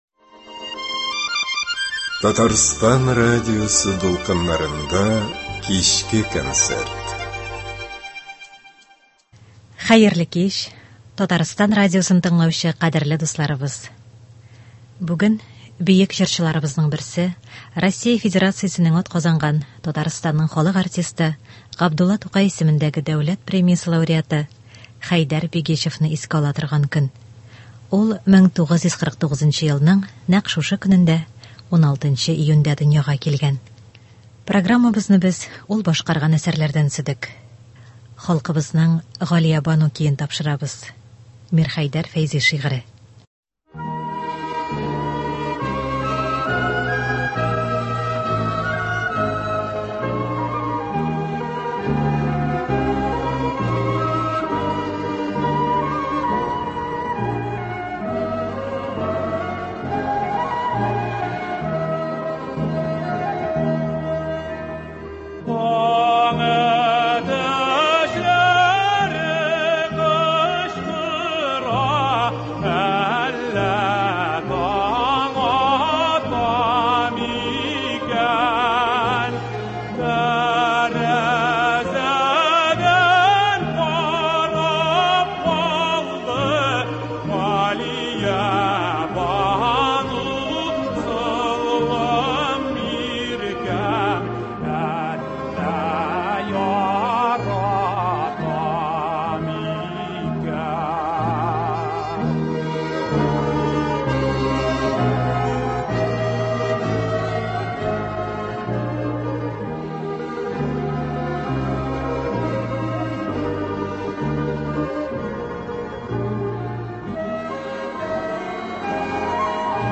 Программабызны без ул башкараган әсәрләрдән төзедек.